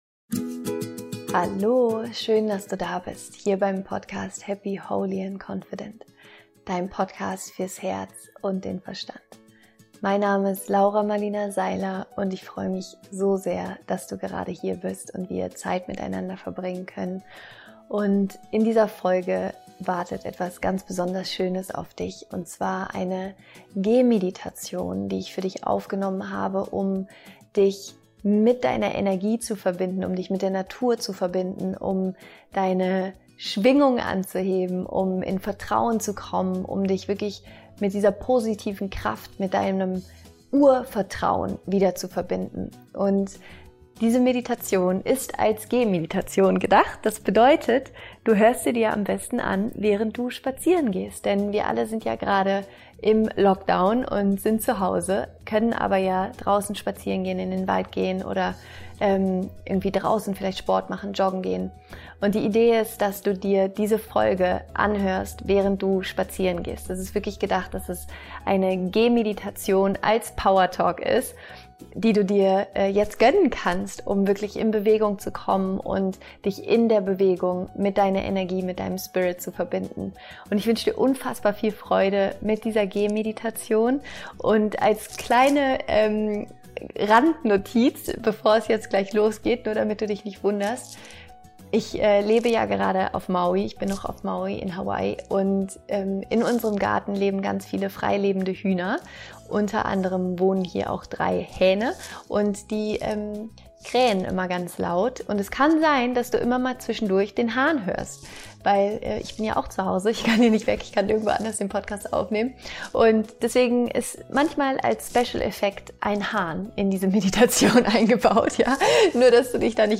Diese Meditation ist als Gehmeditation gedacht.